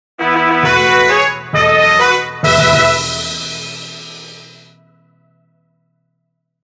効果音 ファンファーレ